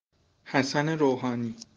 1. ^ Standard Persian pronunciation: [hæˈsæn-e ɾowhɒːˈniː]
Fa-ir-hassan-rouhani.ogg.mp3